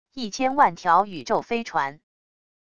1000万条宇宙飞船wav音频